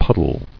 [pud·dle]